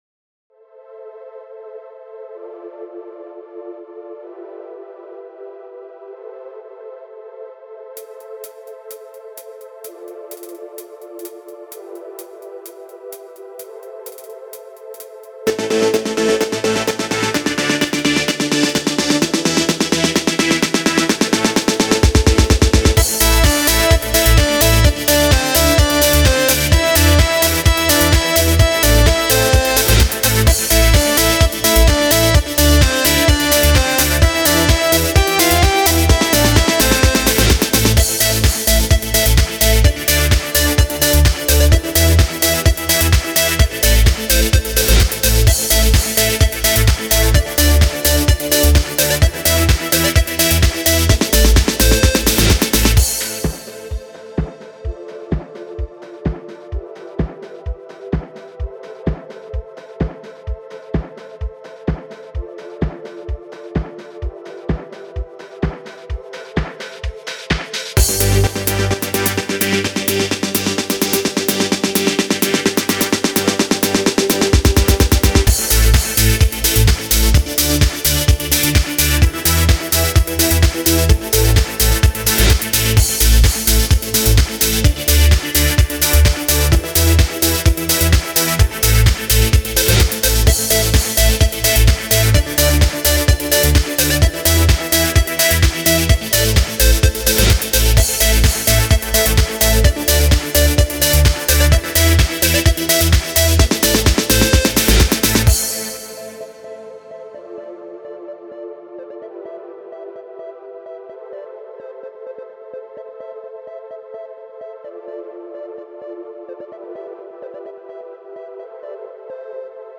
(минус)
Стиль: Synthpop